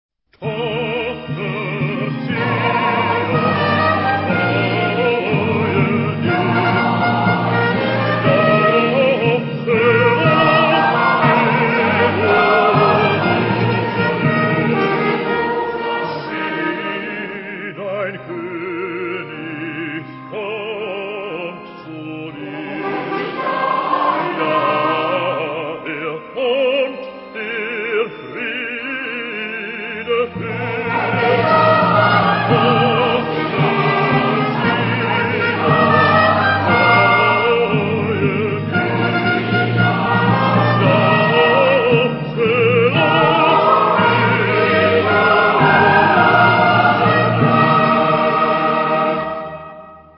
Genre-Style-Forme : Sacré
Caractère de la pièce : religieux ; contemplatif
Type de choeur : SATB  (1 voix mixtes )
Instruments : Instrument mélodique (ad lib) ; Clavier (1)
Tonalité : mi bémol majeur